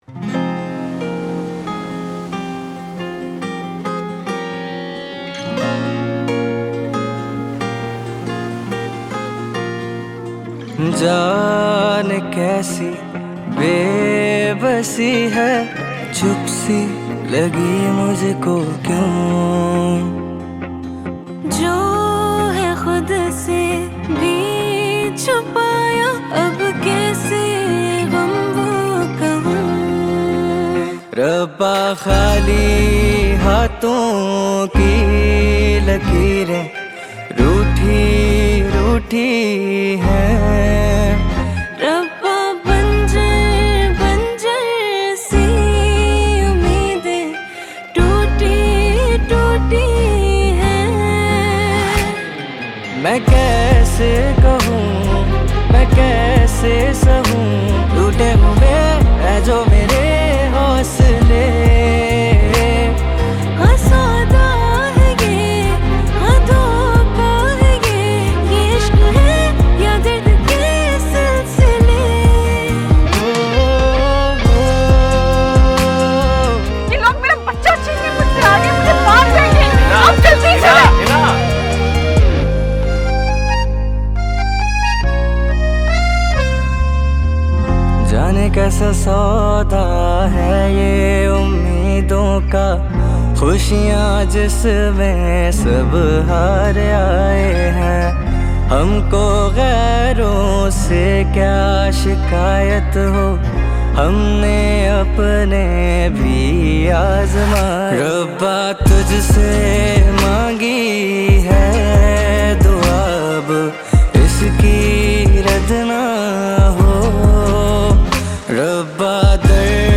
Pakistani drama song